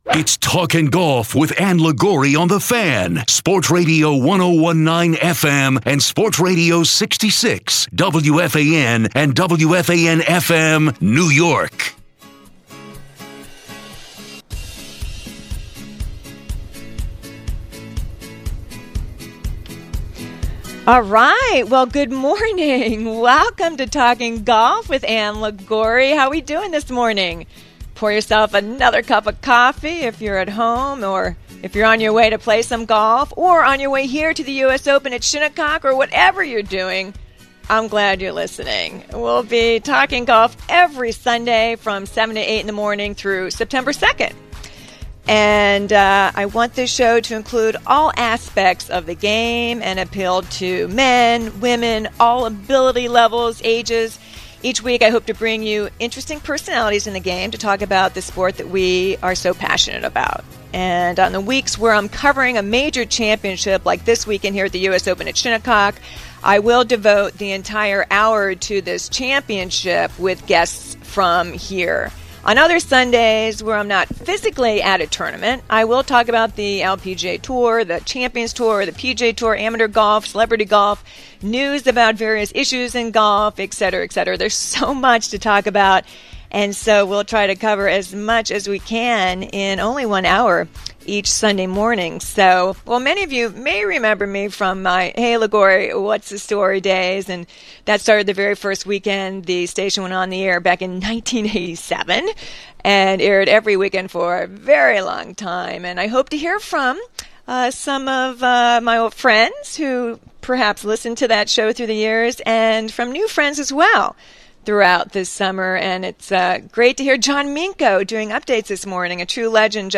broadcasts from the US Open at Shinnecock Hills Golf Club which swarmed in controversy during and after 3rd round play.